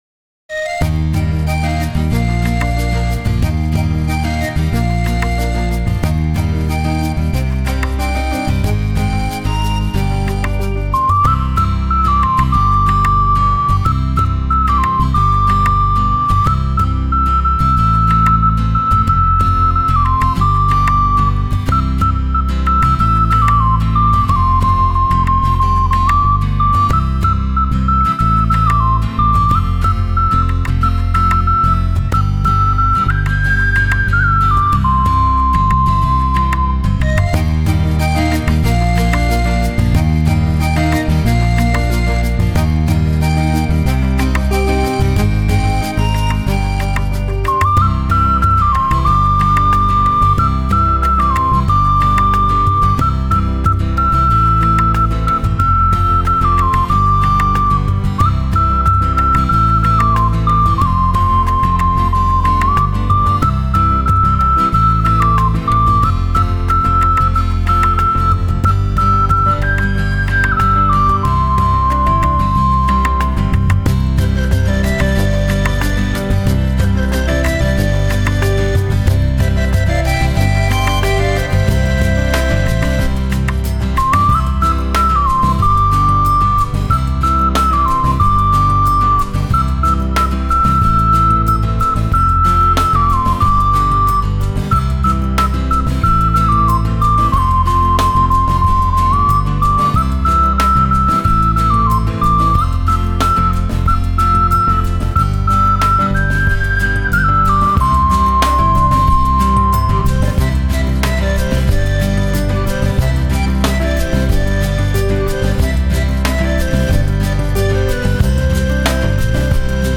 佛曲音樂